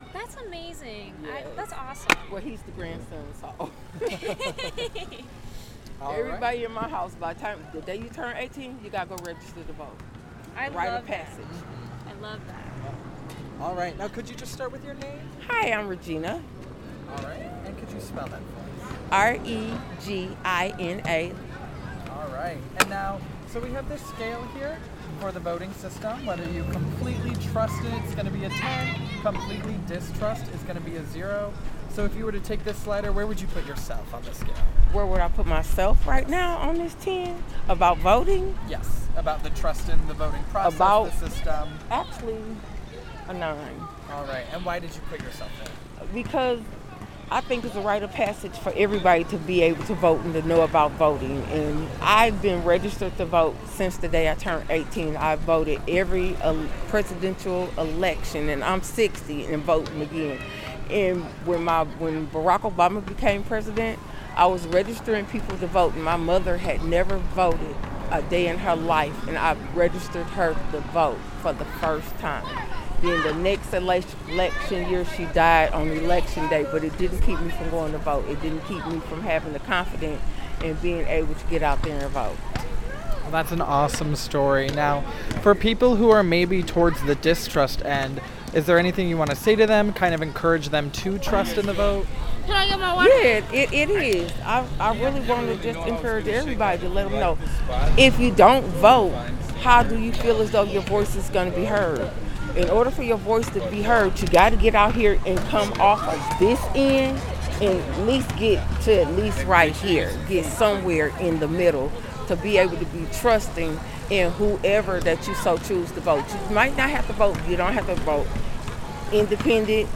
Rooted and Rising Block Party